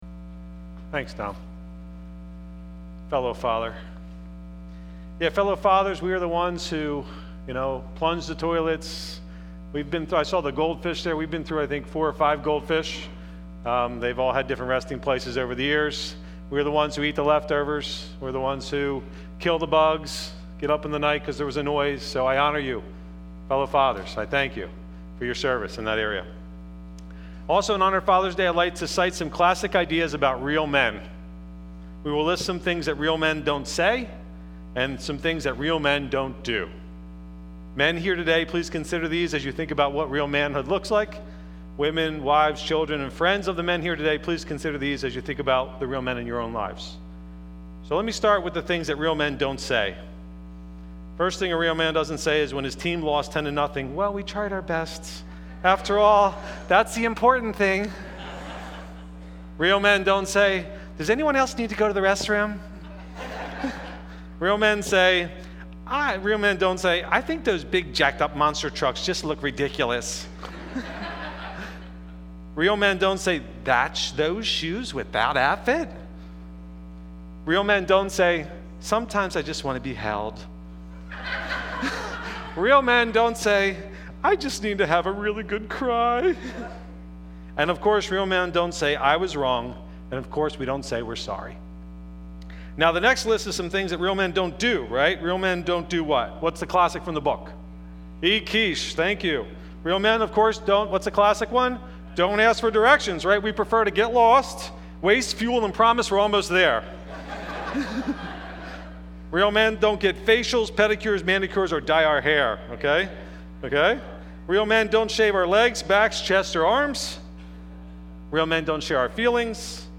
Father's Day message 2013